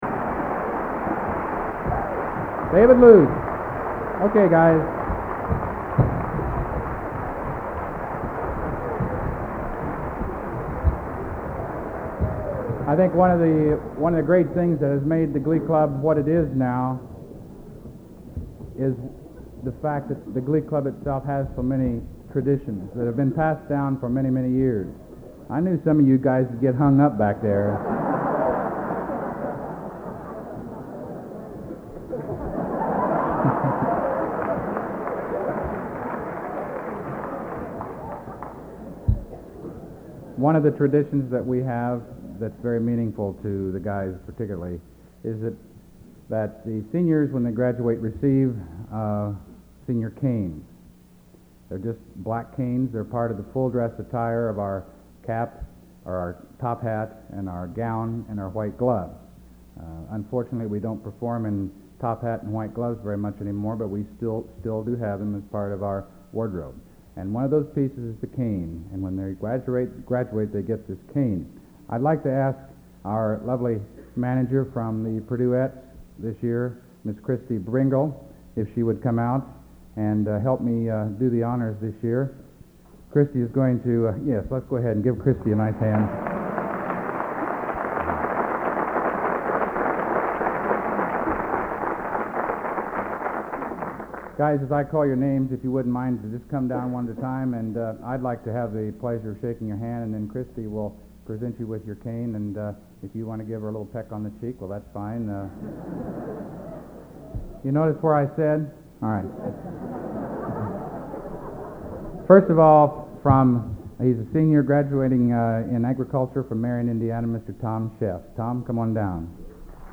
Collection: End of Season, 1983
Location: West Lafayette, Indiana